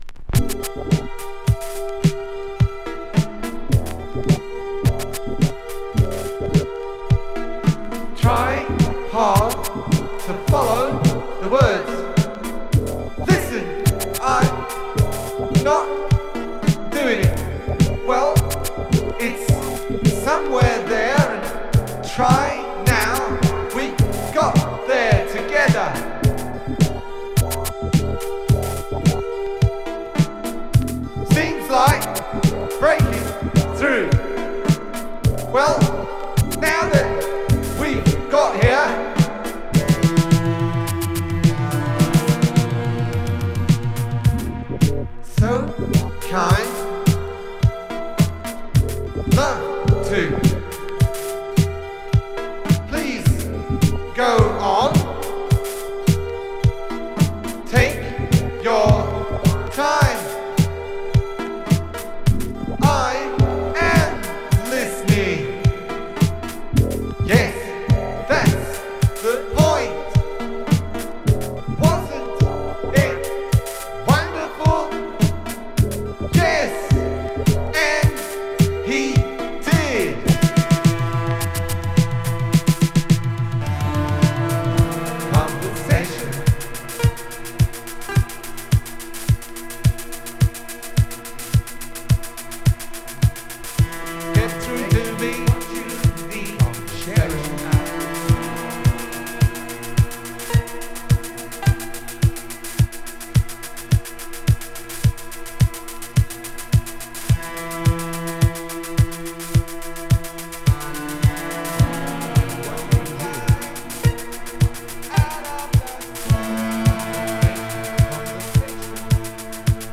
ニューウェイヴ/インダストリアル/エクスペリメンタル寄りのサウンドが特徴です。